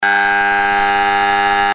AVISADOR ACÚSTICO ELECTROMAGNÉTICO - SONIDO CONTINUO
Serie: AVISADORES ACÚSTICOS ELECTROMAGNÉTICOS
Elevado rendimiento acústico
105dB
Sonido continuo